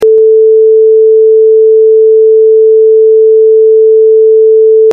Son de référence +6dB
440_plus_6dB.mp3